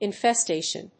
音節in・fes・ta・tion 発音記号・読み方
/ìnfestéɪʃən(米国英語), ˌɪˈnfeˈsteɪʃʌn(英国英語)/